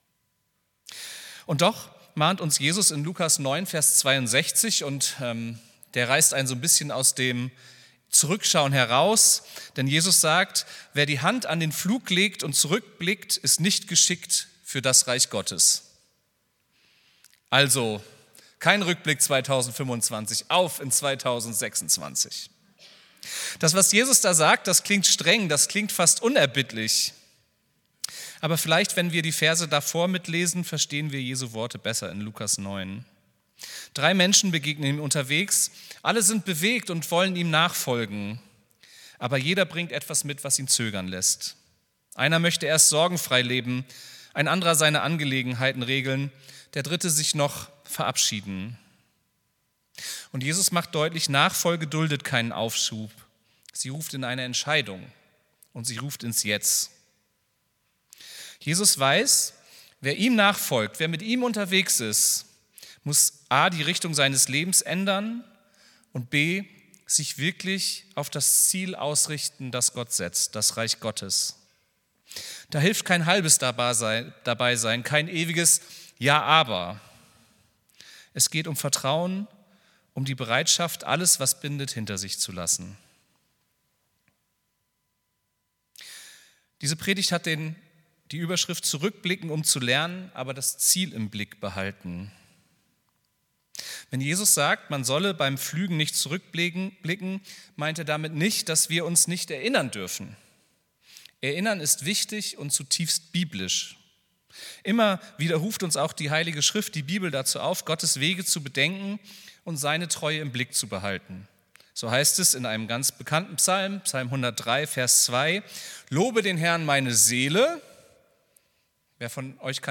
Predigt vom 28.12.2025